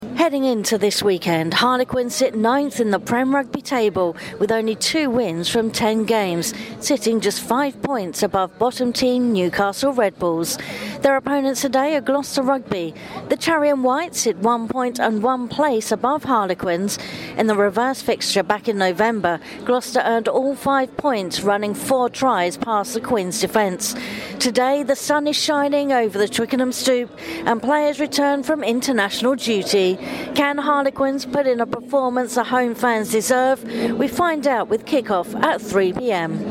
Premiership Rugby action resumes this weekend following the international break and Radio Jackie reports from Twickenham Stoop.